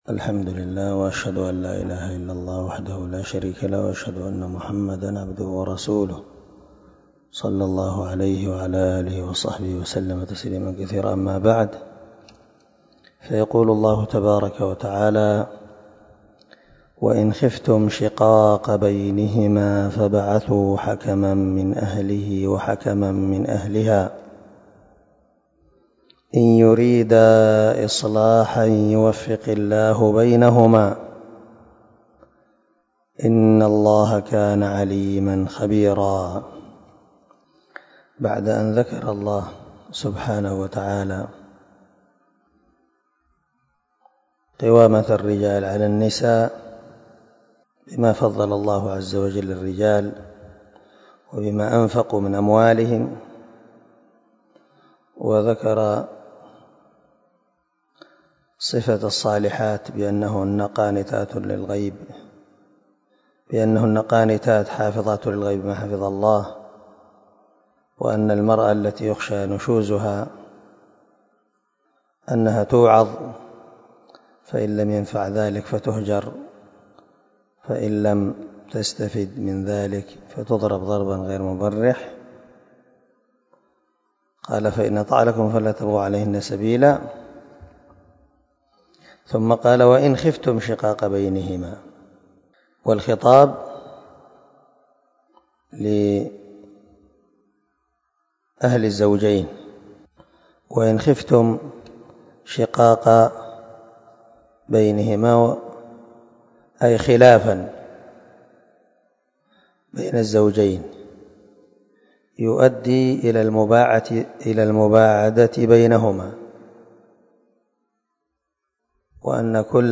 260الدرس 28 تفسير آية ( 35 ) من سورة النساء من تفسير القران الكريم مع قراءة لتفسير السعدي